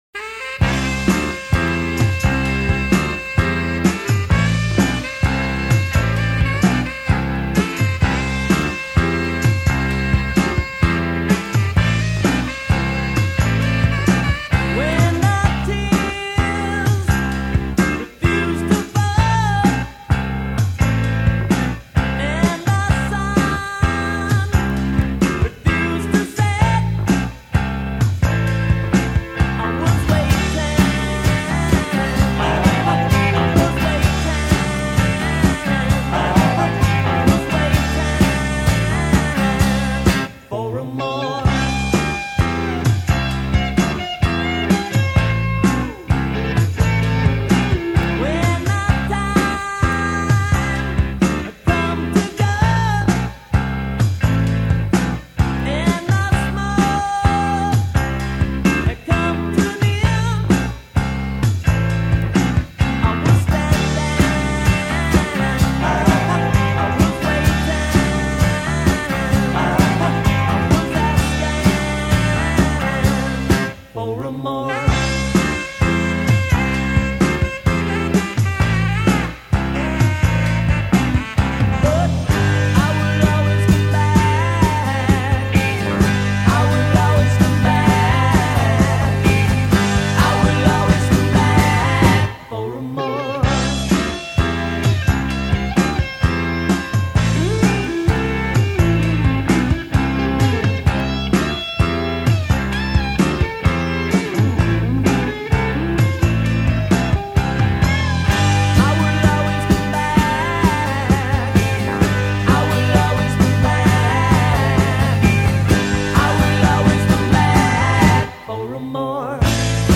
Tracks 4-6 Recorded at Cherokee Studios
Drums.
Lead Vocal and Rhythm Guitar.